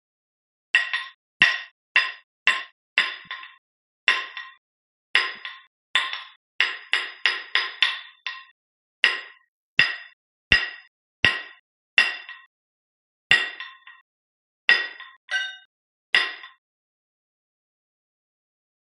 Chisel; Tapping; Light Metallic Tapping With Chisel. Stone Mason At Work.